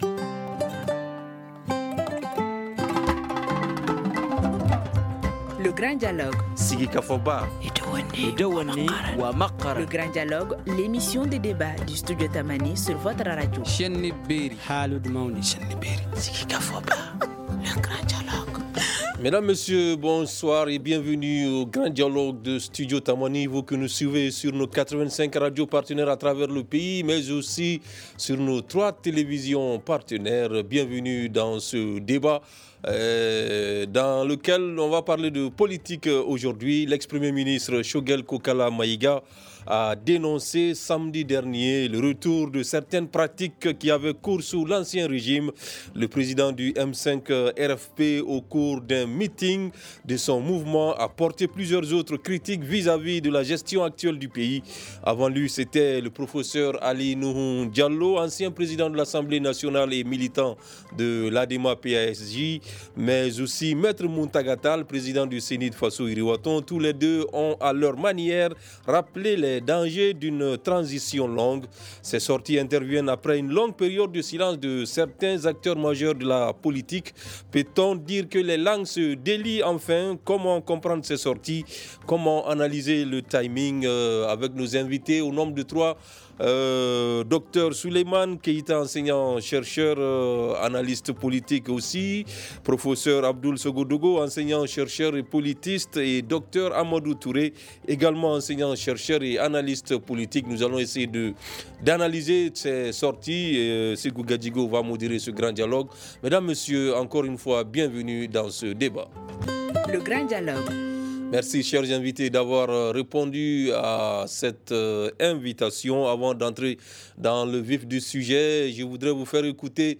enseignant-chercheur, analyste politique